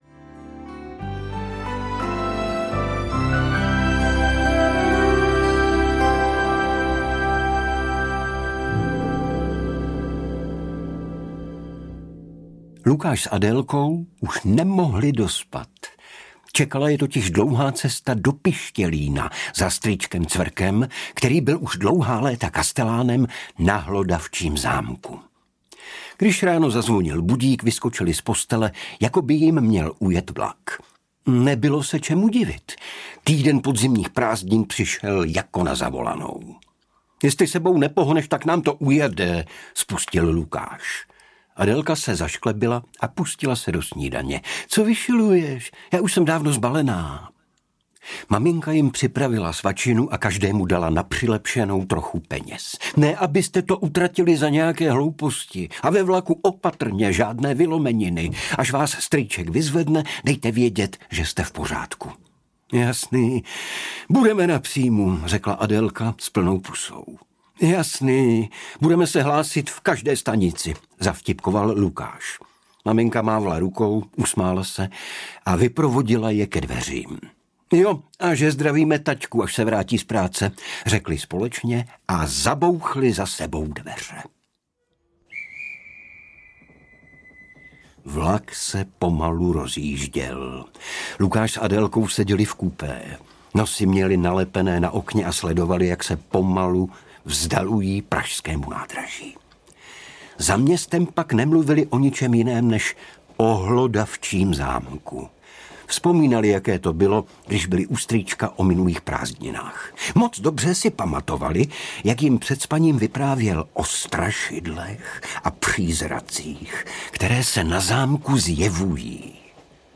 Kouzelný klíč - Konec duchů na Hlodavčím zámku audiokniha
Ukázka z knihy
• InterpretIgor Bareš